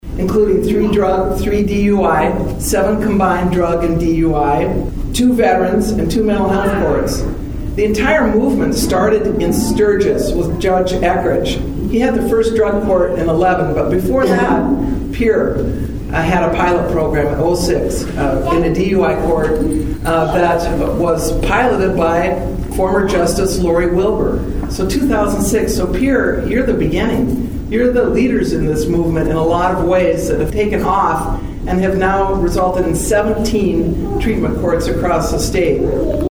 South Dakota Supreme Court Justice Janine Kern speaks at the Sixth Circuit Drug and DUI Treatment Court Program Graduation in Pierre Nov. 12, 2025.